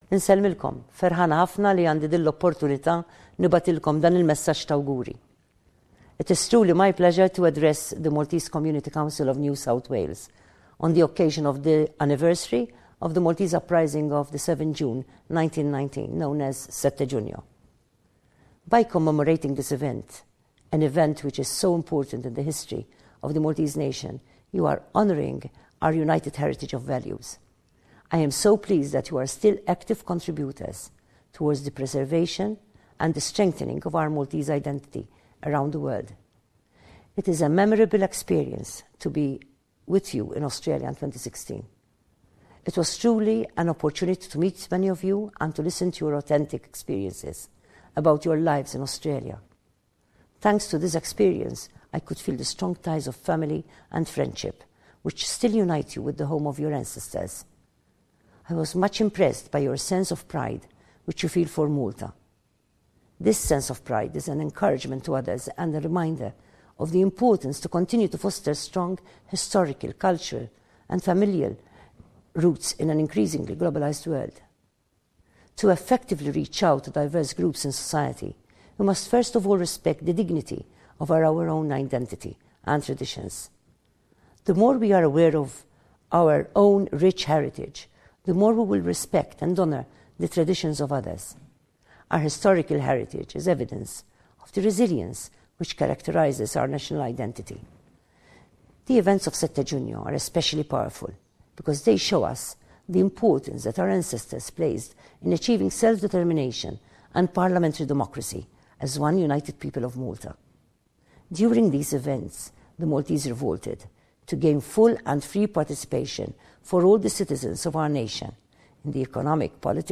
President of Malta – Sette Giugno message to the Maltese in Australia
Message to the Maltese Community in Australia by the President of Malta, Marie-Louise Coleiro Preca on the occasion of Malta's National day of Seventh of June 1919, commemorated by the Maltese Community Council of New South Wales in Civic Park, Pendle Hill. This day commemorates the events which occurred in 1919 when, following a series of riots by the Maltese population, British troops fired into the crowd, killing four people.